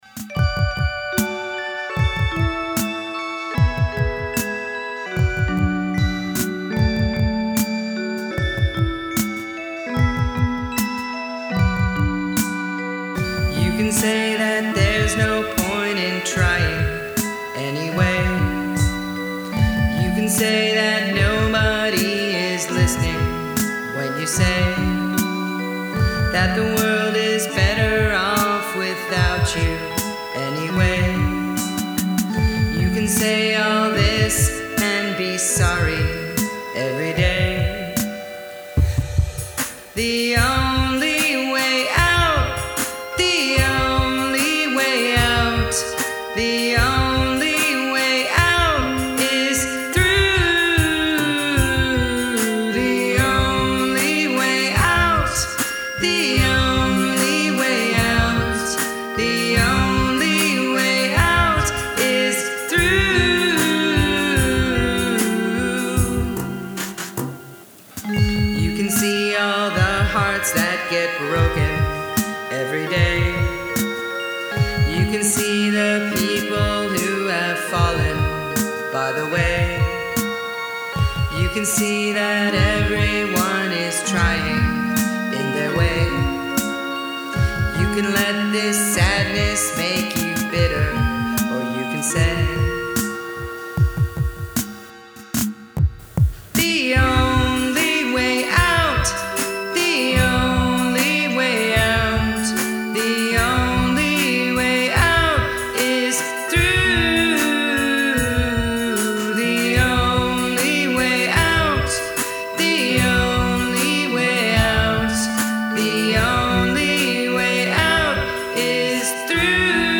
Downward Modulation